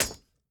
Sword Blocked 3.ogg